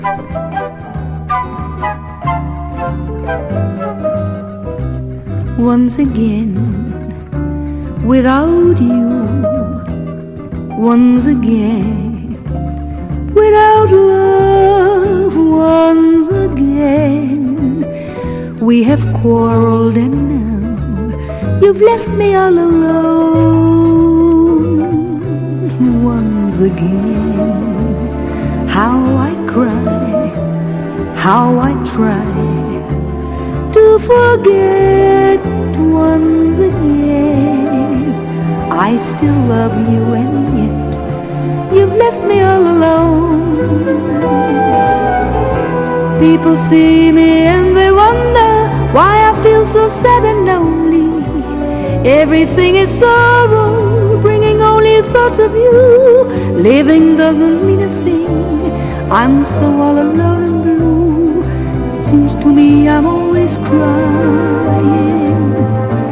JAZZ / DANCEFLOOR / SOUL JAZZ / EASY LISTENING / MOD
キラーなモッド・ファンク/モッド・ジャズ/ソウル・ジャズが満載！
ブラスの鳴りが明らかに一級品な極上アルバム！